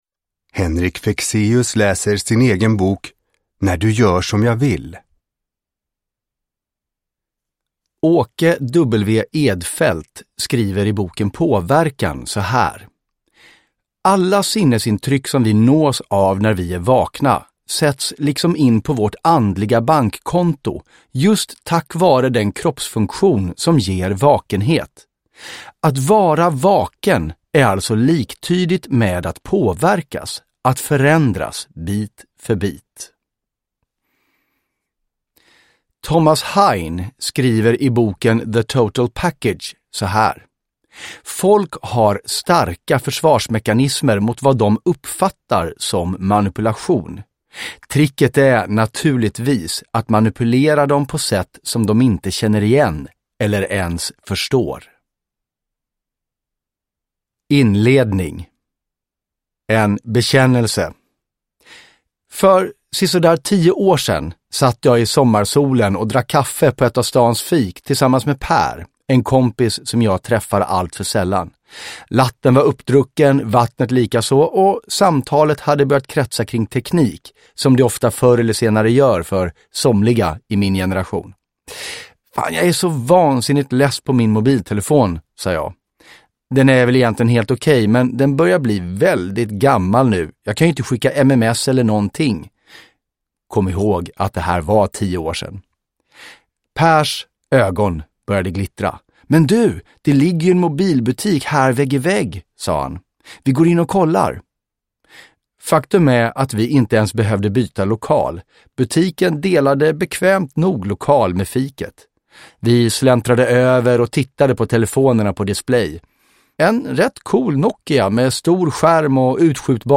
Uppläsare: Henrik Fexeus
Ljudbok